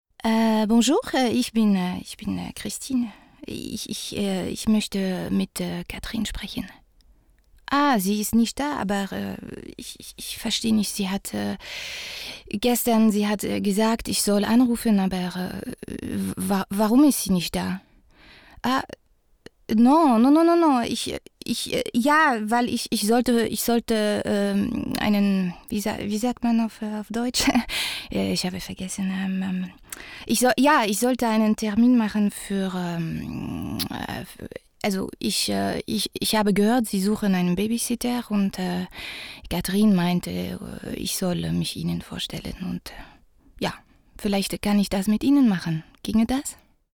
Jung (18-30)
Commercial (Werbung)